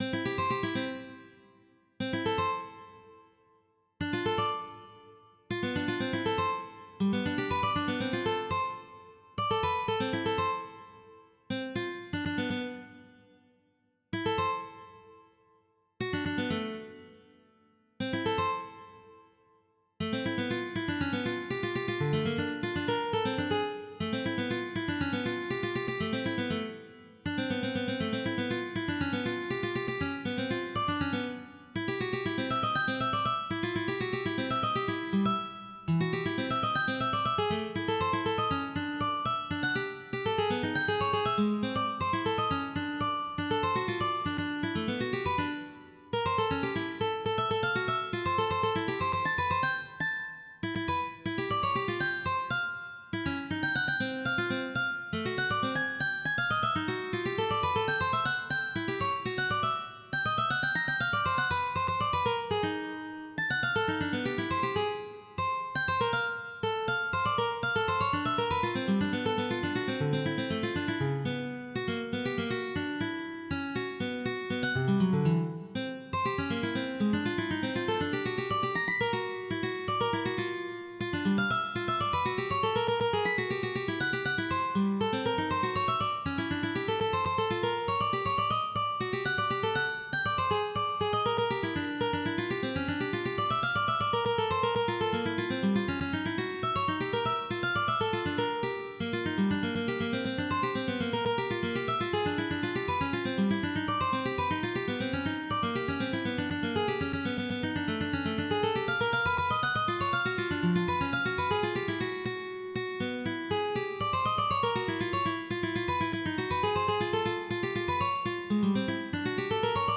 Pop Energetic 02:00